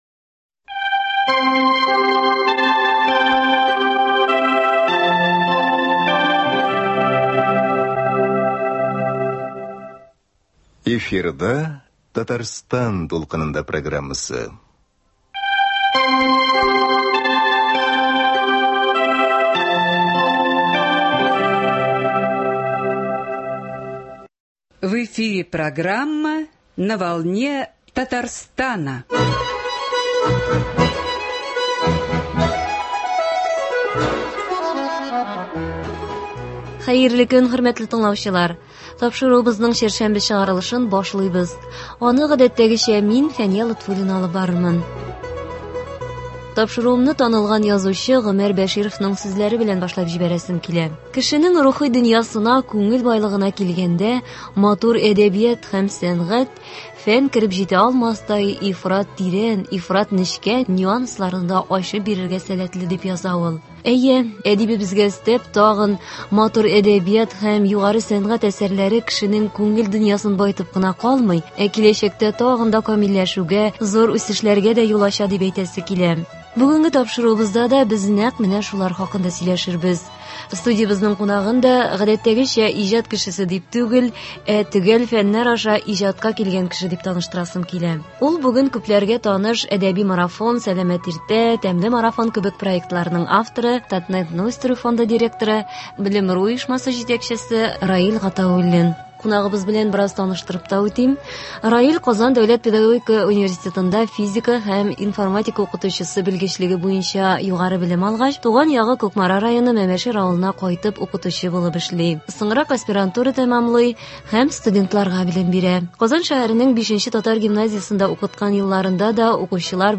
Бүгенге тапшыруыбызда да без нәкъ менә шулар хакында сөйләшербез. Студиябезнең кунагын да гадәттәгечә иҗат кешесе дип түгел, ә төгәл фәннәр аша иҗатка килгән кеше дип таныштырасым килә.